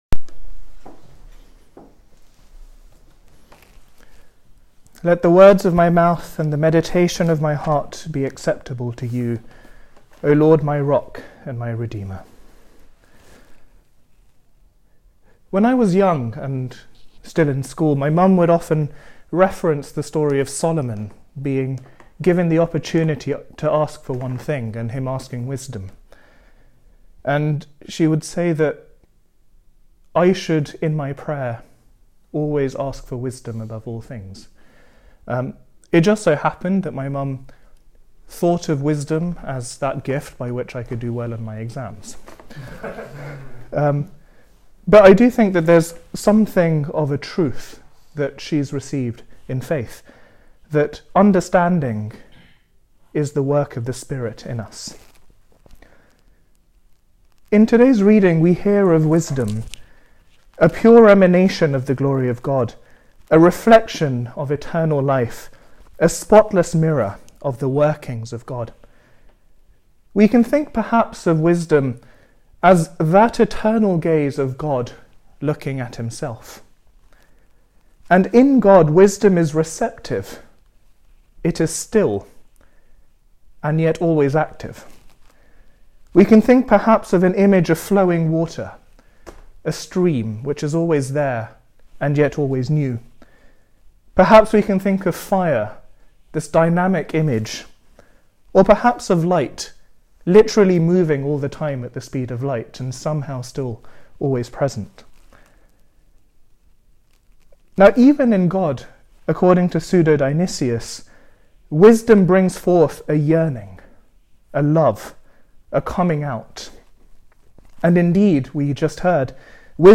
Reading: Wisdom 7:22-8:1 The following homily was preached to the student brothers during compline.